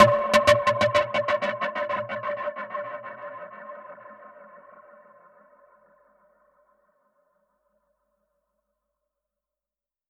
Index of /musicradar/dub-percussion-samples/95bpm
DPFX_PercHit_B_95-04.wav